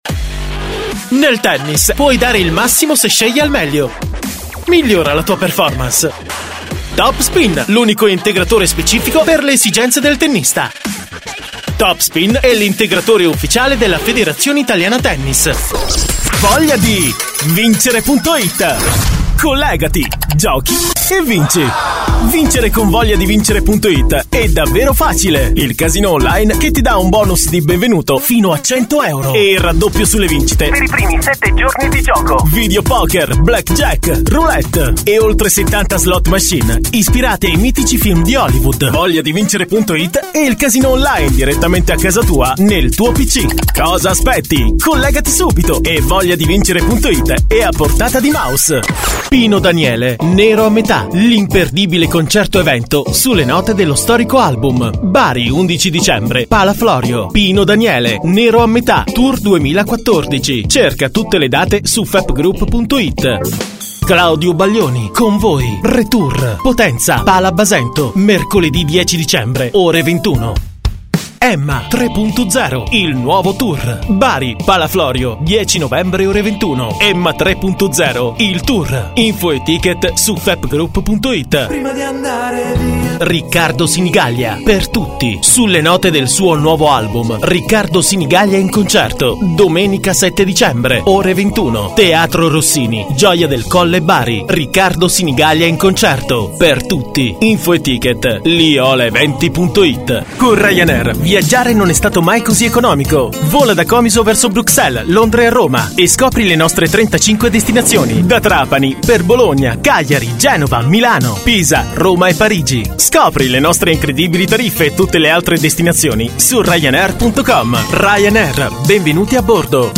Kein Dialekt
Sprechprobe: Werbung (Muttersprache):
spots.mp3